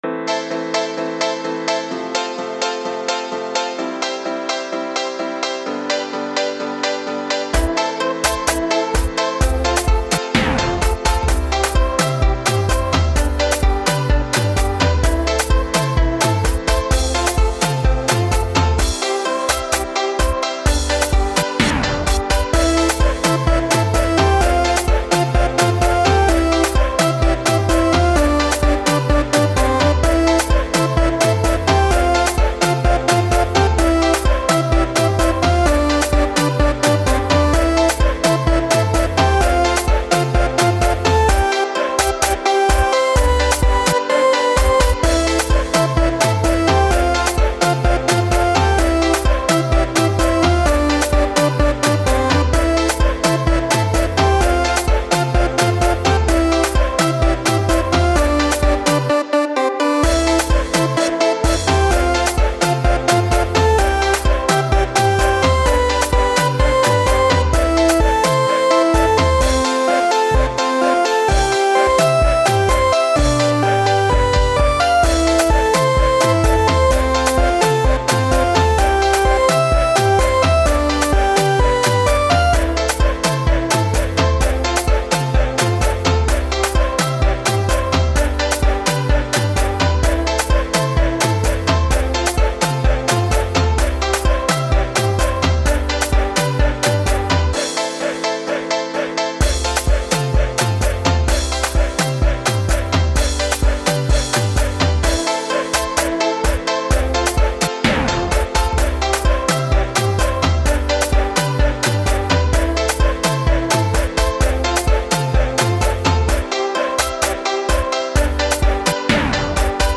telechaje raboday la